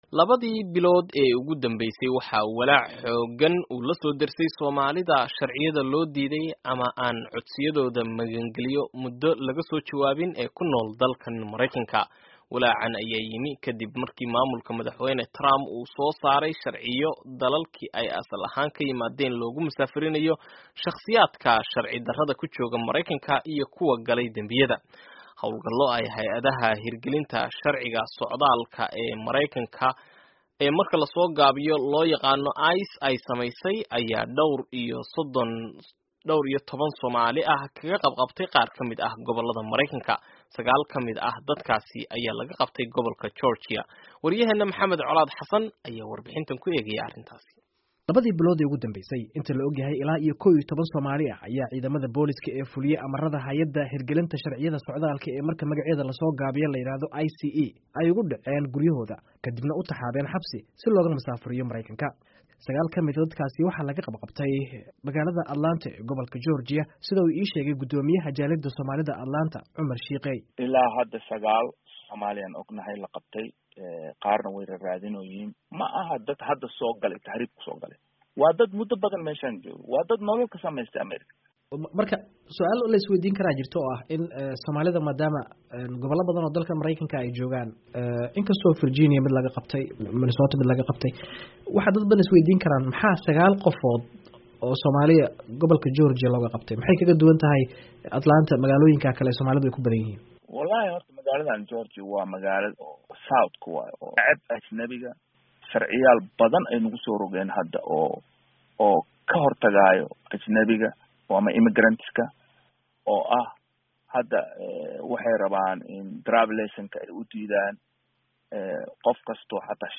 Warbixinta Soomaalida lagu xiray Mareykanka